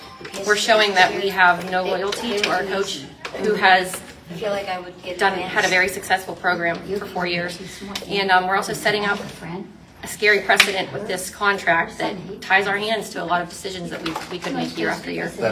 The vote was 5-4.  Board member Jessica Clawson